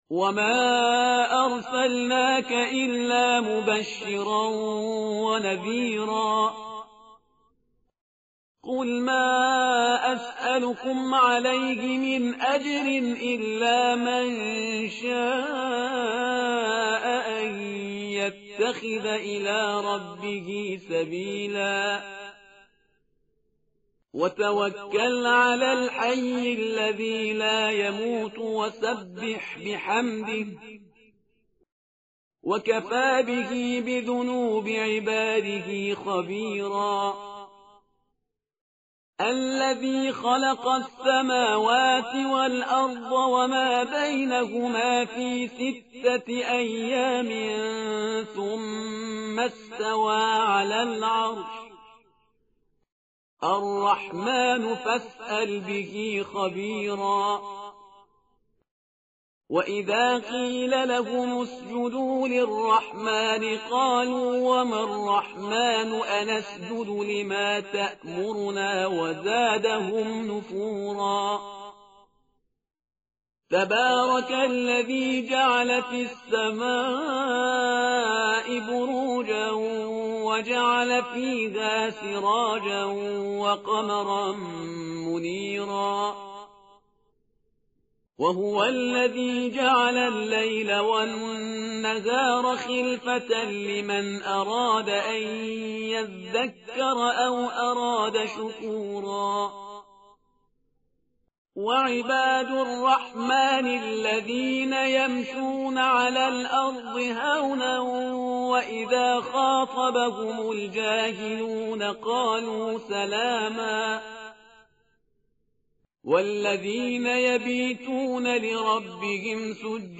tartil_parhizgar_page_365.mp3